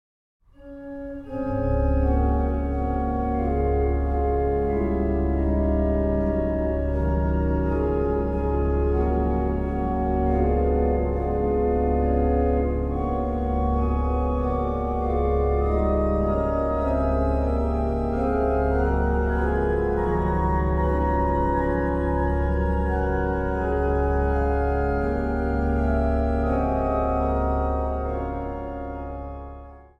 König-orgel
Sint Stevenskerk te Nijmegen